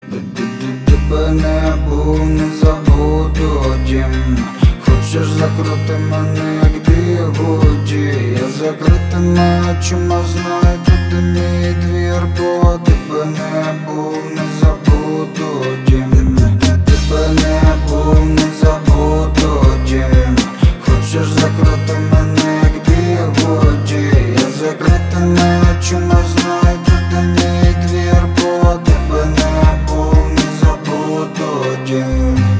• Качество: 320, Stereo
мужской голос
спокойные